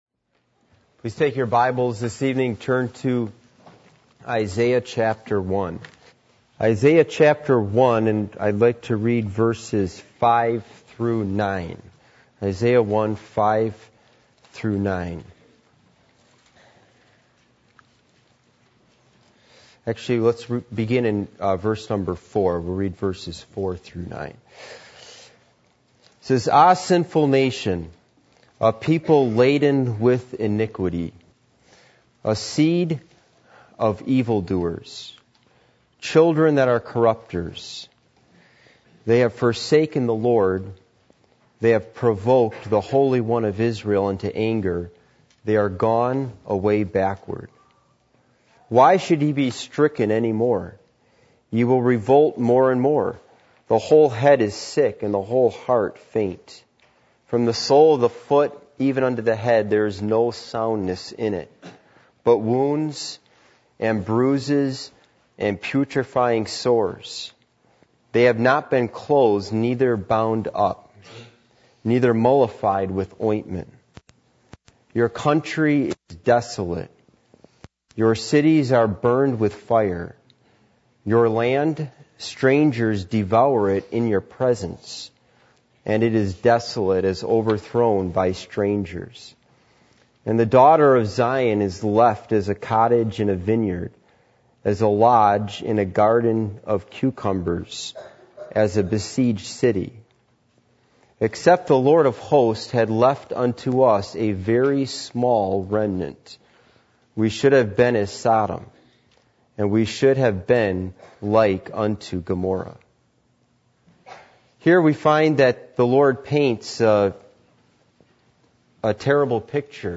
Passage: Isaiah 1:4-9 Service Type: Midweek Meeting